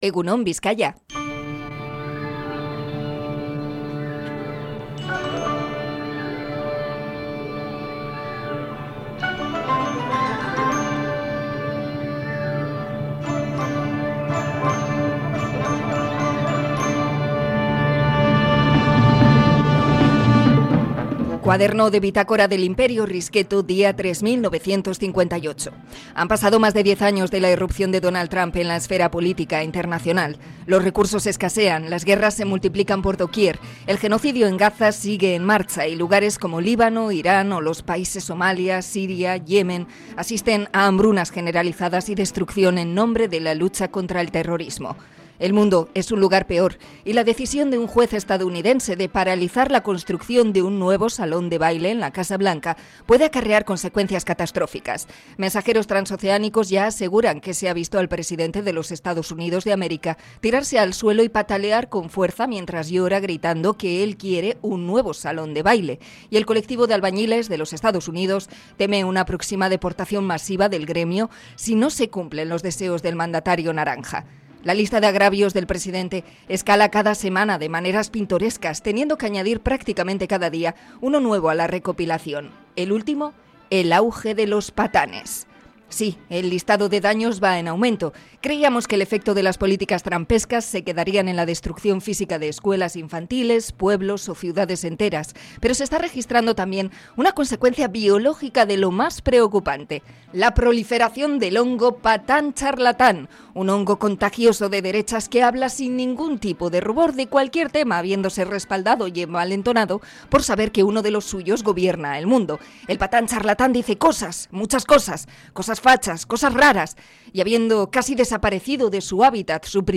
Comentario sobre lo respaldados que se sienten los patanes con Trump